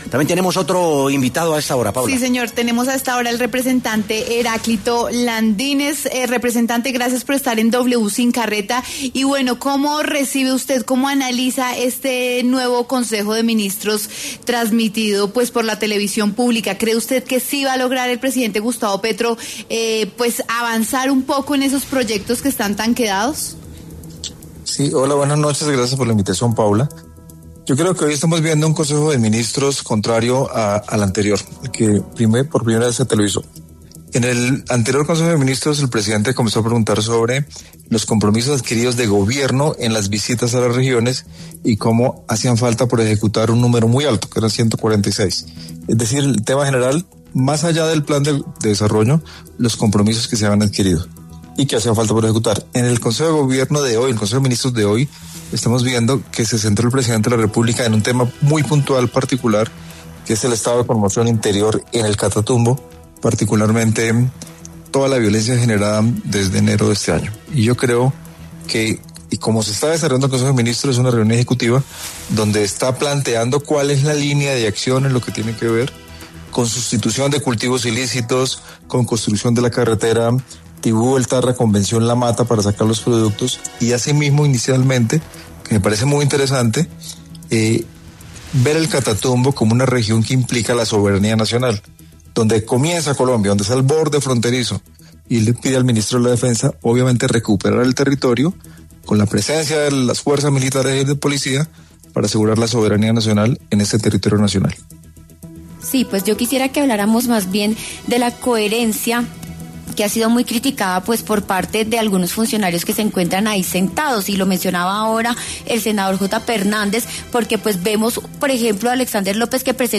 Heráclito Landinez, representante del Pacto Histórico, pasó por los micrófonos de W Sin Carreta, para analizar el consejo de ministros del presidente Gustavo Petro que fue transmitido este lunes, 3 de marzo.